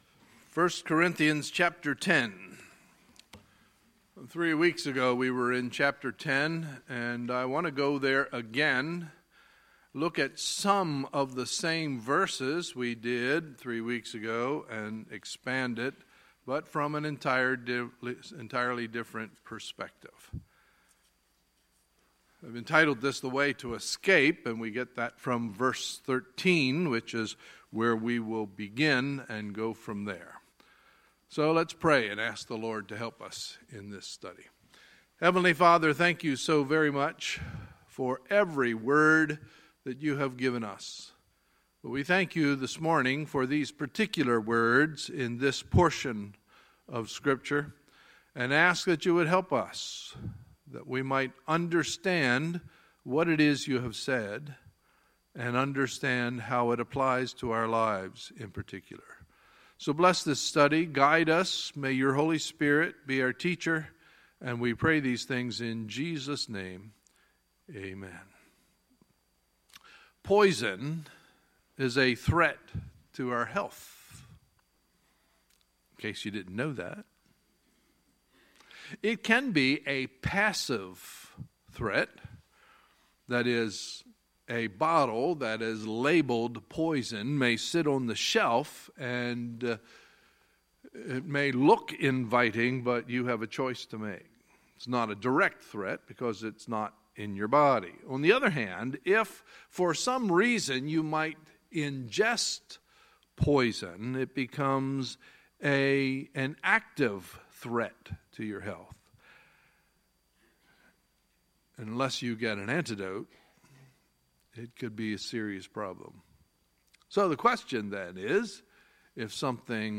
Sunday, April 23, 2017 – Sunday Morning Service